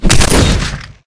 heavy punch
anime cartoon crunch impact punch splat sound effect free sound royalty free Movies & TV